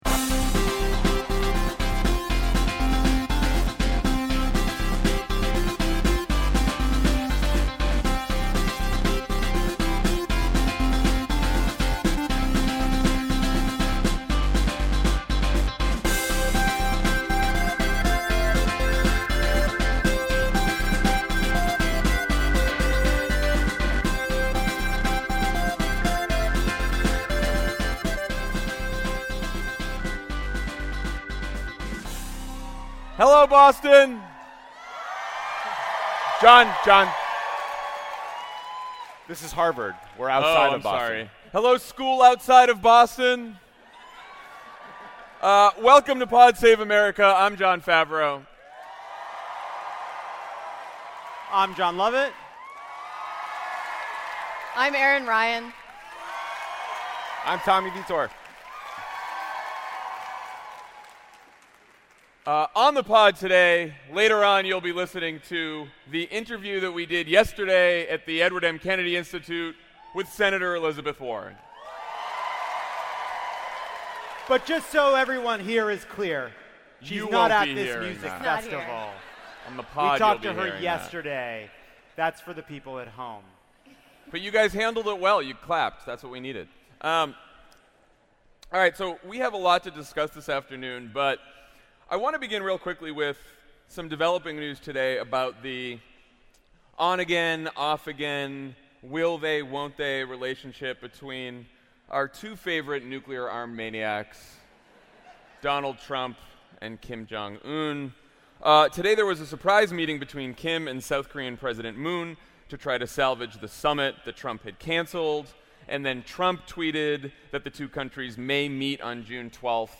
on stage at Boston Calling, and then our interview at the Edward M. Kennedy Institute with Senator Elizabeth Warren.